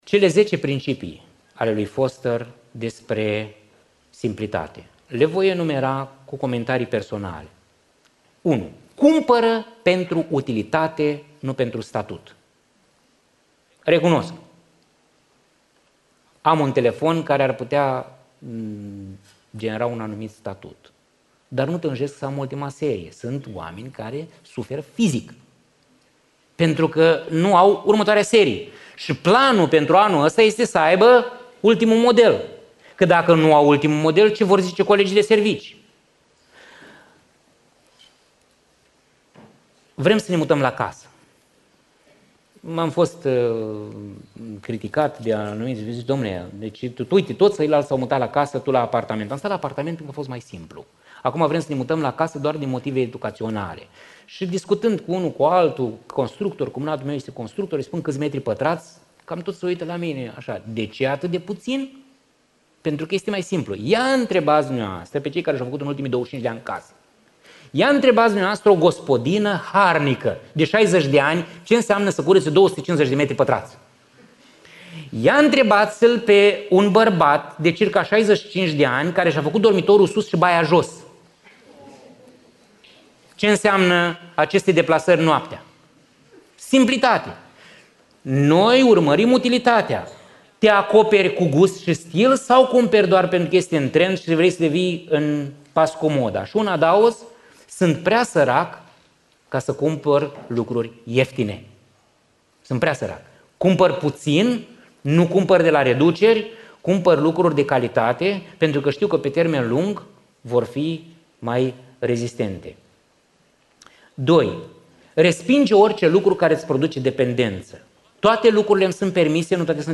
În biserica Iris din Cluj